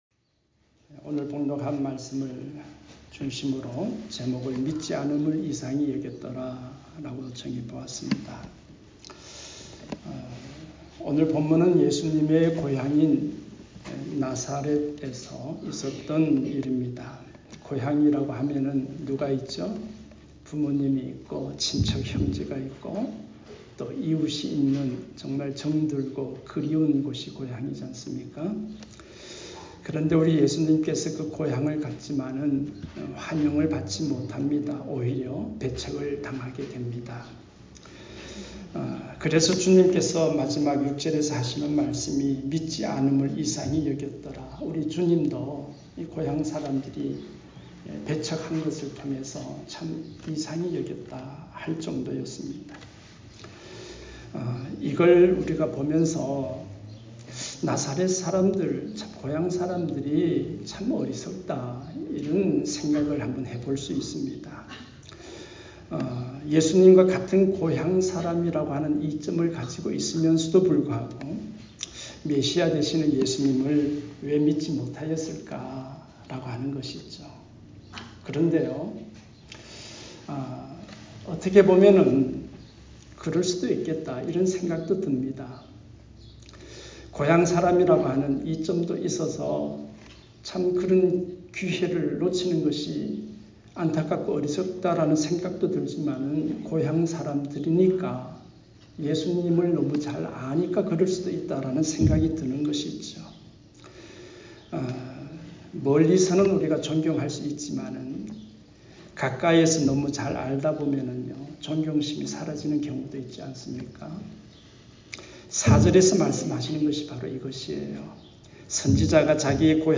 믿지 않음을 이상히 여겼더라 ( 막6:1-6 ) 말씀